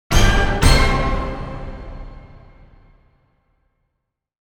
Breaking News Transition Sound Effect
Professional news broadcast intro sound effect creates an instant sense of urgency and importance.
Perfect for social media reels and shorts that need a strong and modern news alert atmosphere.
Genres: Sound Logo
Breaking-news-transition-sound-effect.mp3